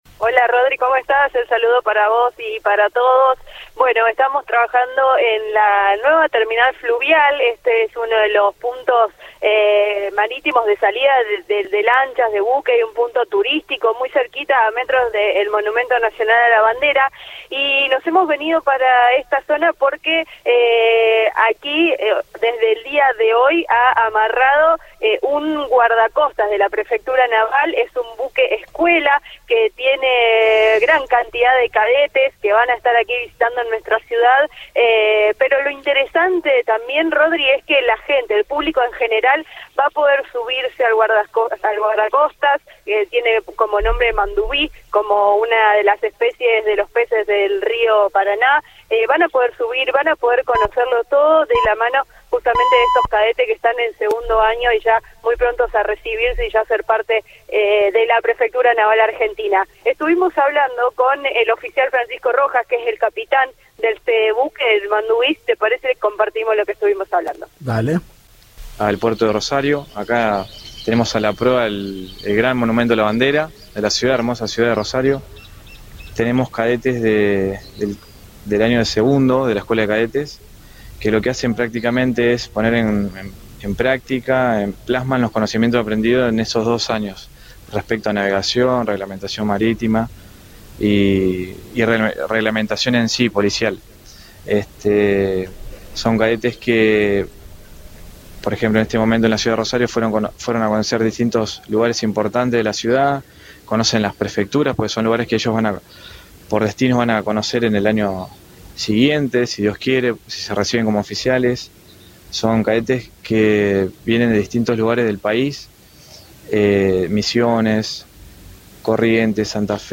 dialogó con el móvil de Cadena 3 Rosario